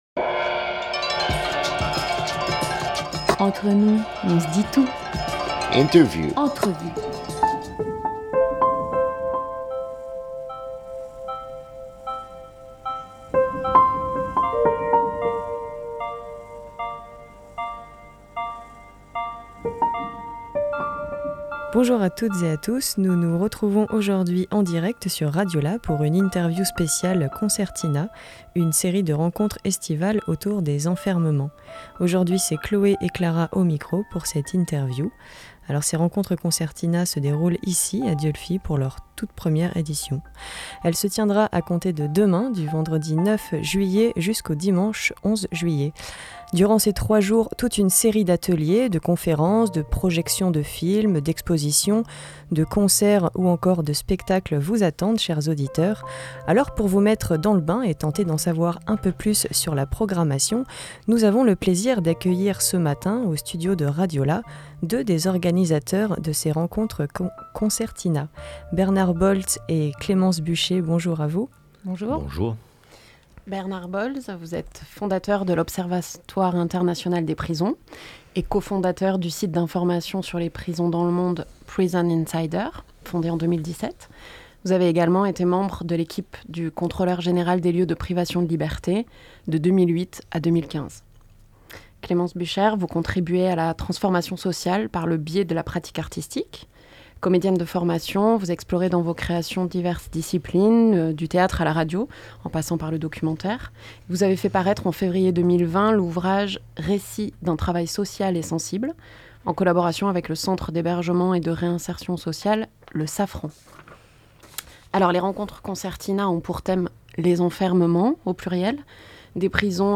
8 juillet 2021 15:16 | Interview